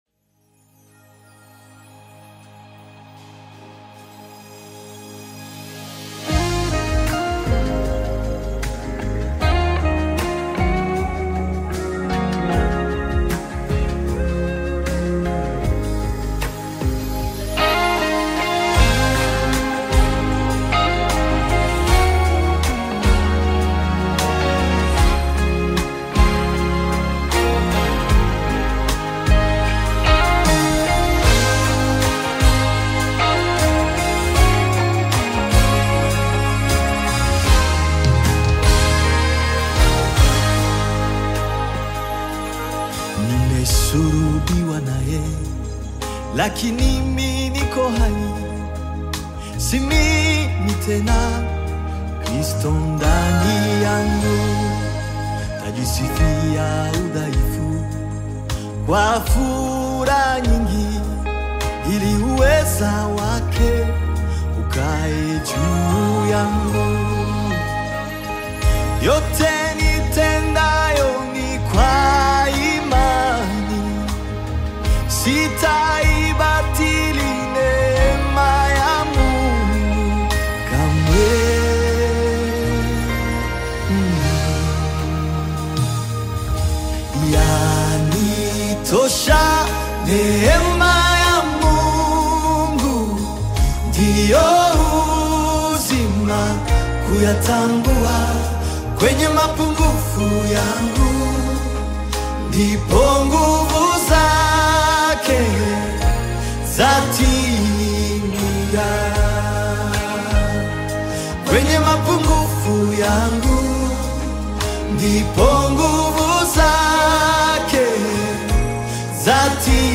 Nyimbo za Dini music
Gospel music track
Rwandan gospel artist, singer, and songwriter